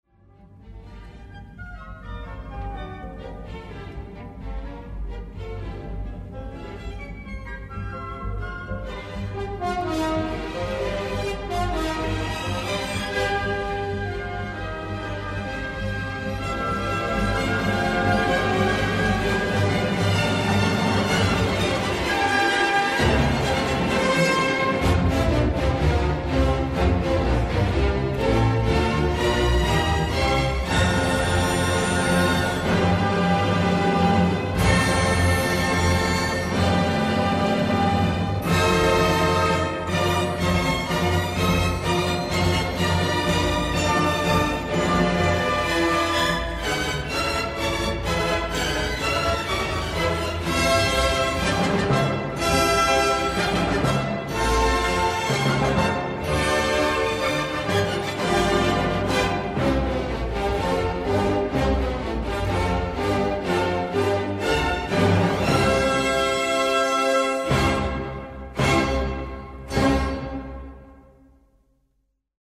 Sinfonía no. 4Johannes Brahms
Los partidarios de esta hipótesis atribuyen a las ideas del poeta griego el tono sombrío y austero de la música de esta sinfonía, cuyo carácter es trágico y heroico a la vez.